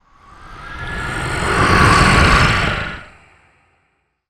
dark_wind_growls_02.wav